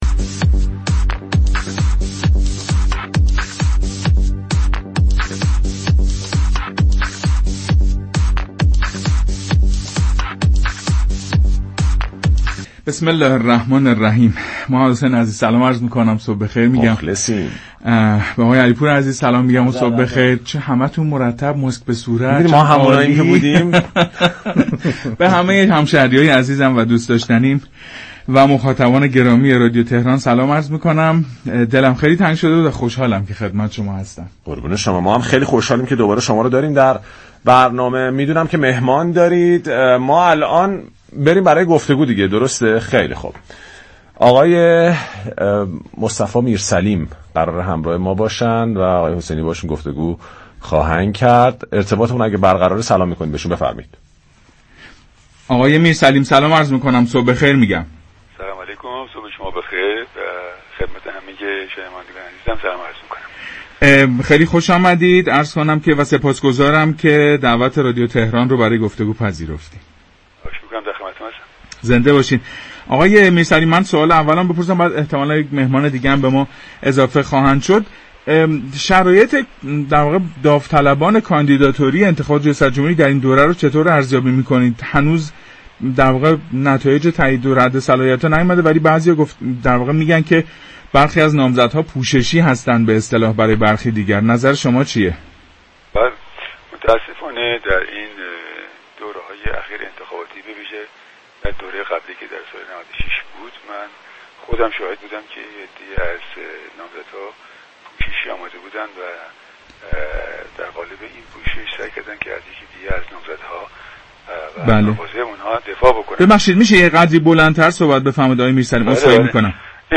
به گزارش پایگاه اطلاع رسانی رادیو تهران، برنامه پارك شهر 1 خرداد با موضوع نامزدهای پوششی ریاست جمهوری در گفتگو با مصطفی آقا میرسلیم و سیدمحمدعلی ابطحی از رادیو تهران پخش شد.